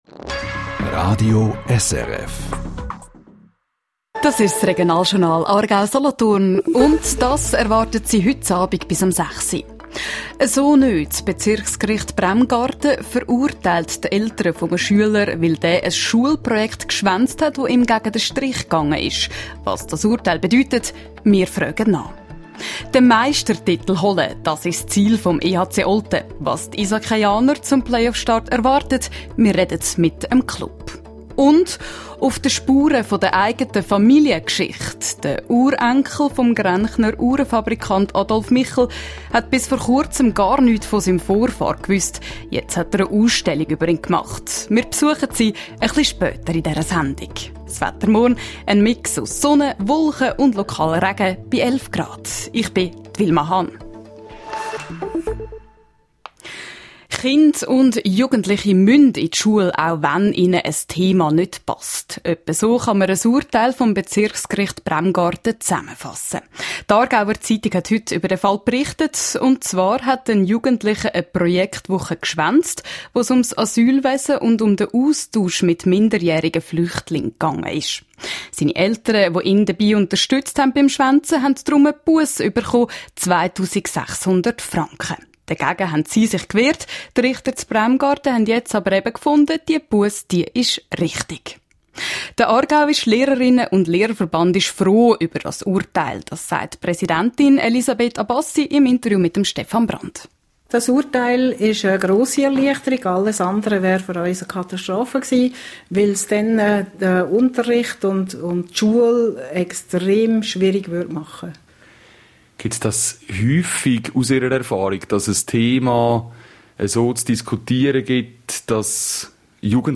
Bericht Interview srf Regionaljournal Aargau-Solothurn